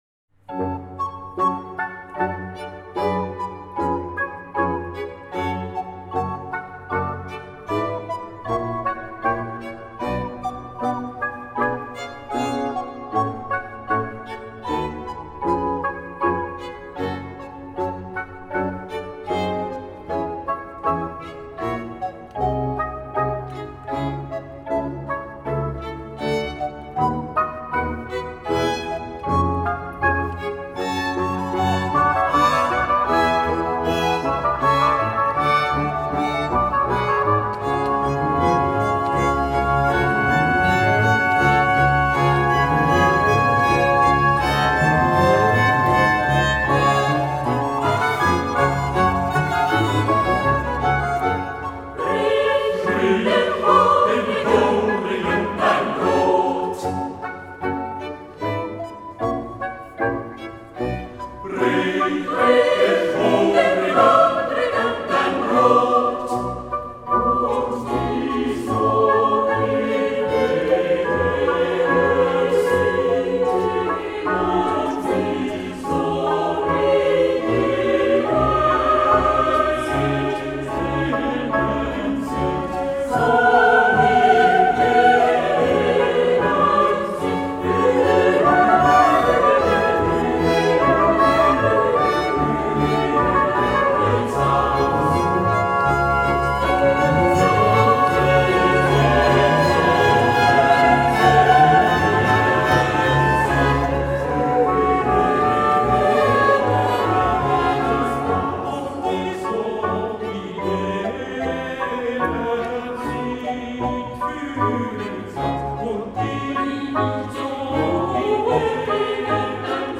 Eglise St François – Lausanne
Ensemble vocal Euterpe